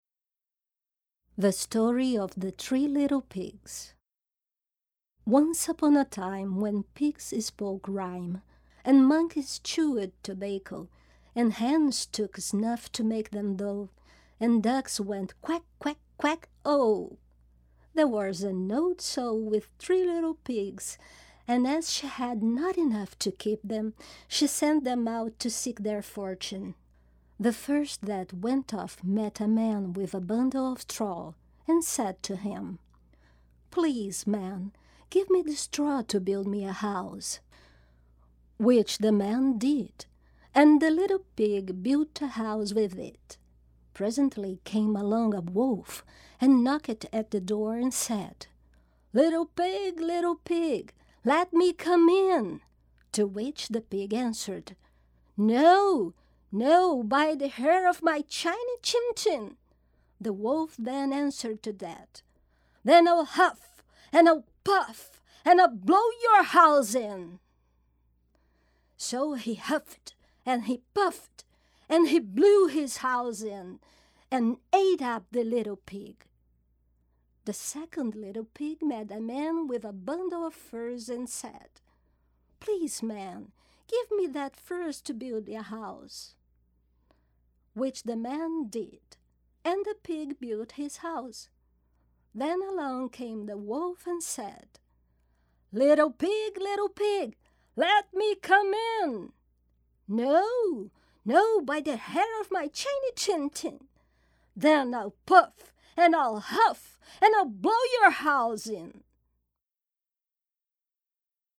• Feminino
Inglês - América do Norte
demo em inglês